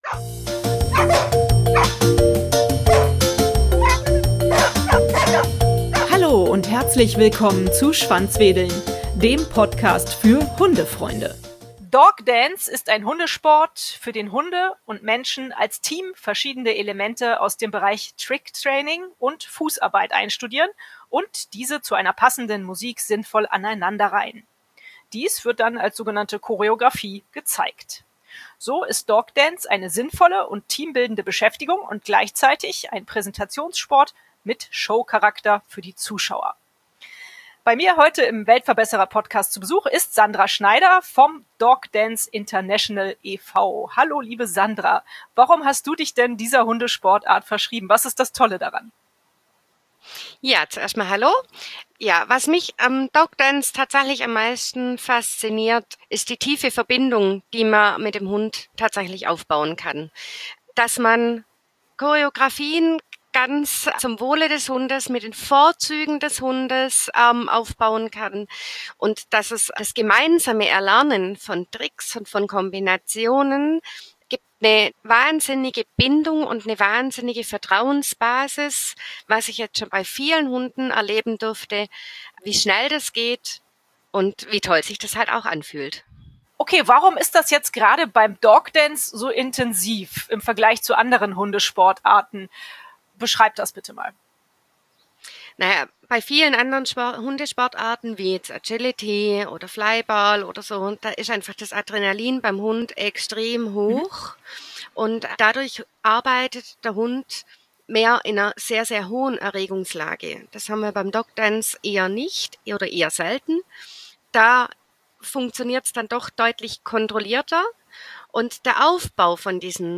Sie klärt uns heute über diesen interessanten Hundesport auf.